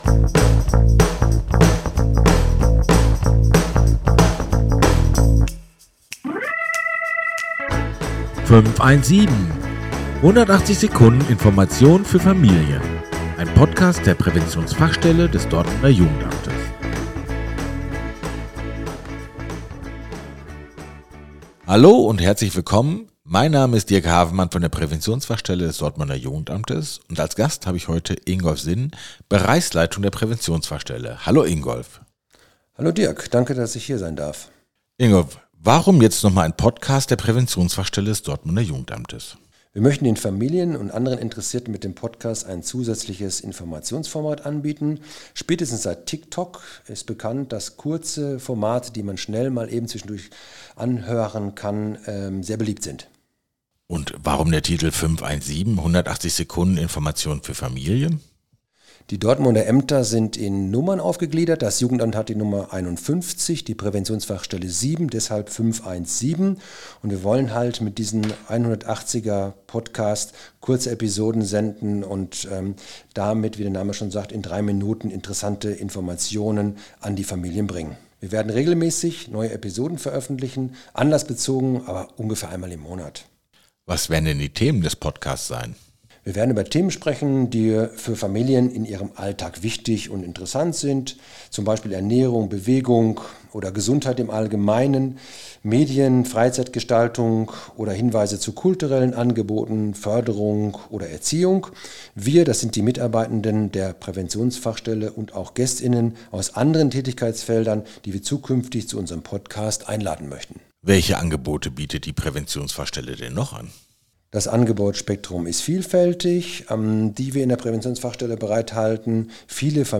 In rund 180 Sekunden sind zu Themen wie der Willkommensbesuch, die Arbeit einer Hebamme oder Mehrsprachigkeit interessante Menschen zu Gast, die im Gespräch mit Fachkräften der Präventionsfachstelle Einblicke in ihre Arbeit bzw. ihre Themen geben.